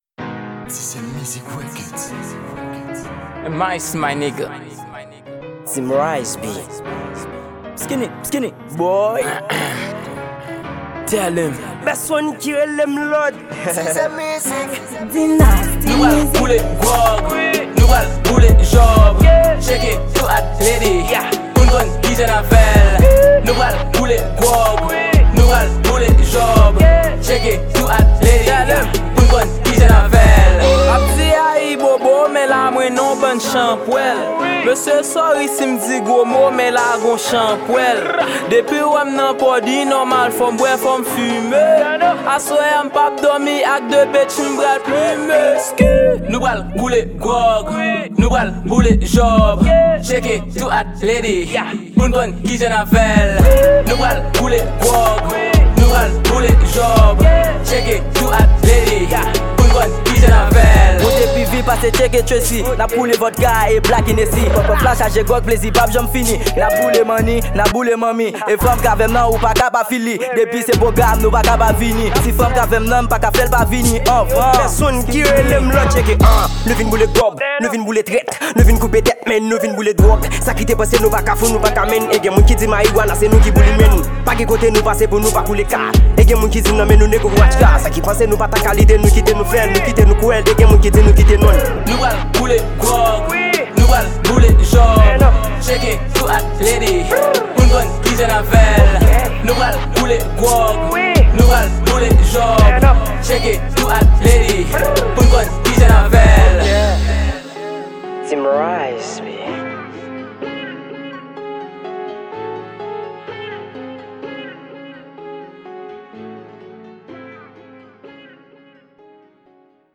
Genre;: Trap